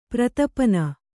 ♪ pratapana